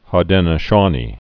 (hô-dĕnə-shônē)